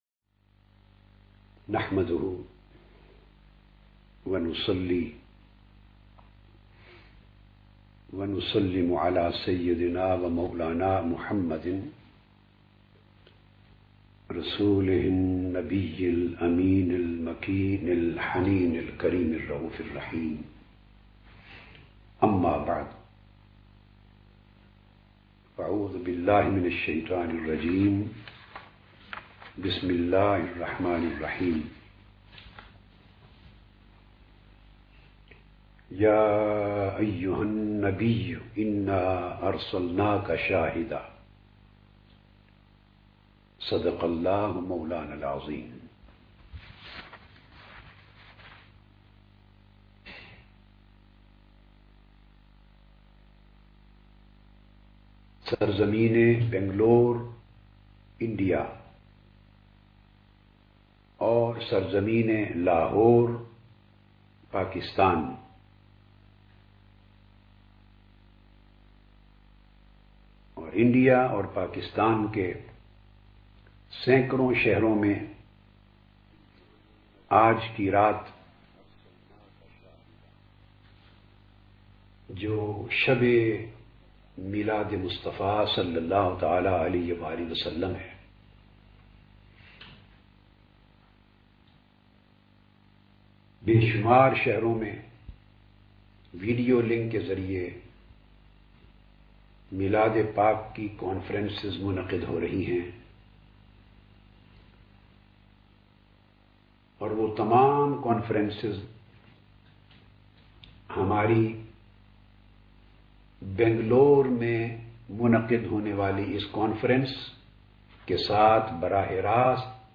Audio mp3 speech dr tahir ul qadri mafhoom e Nabuwat download
Mafhoom-e-Nabuwwat aur Shan-e-Nabuwwat-e-Muhammadi S.A.W.W Mafhoom-e-Nabuwwat aur Shan-e-Nabuwwat-e-Muhammadi S.A.W.W Event : International Mawlid Conference 2014 Click here to sponsor this speech Download this speech in .MP3 format...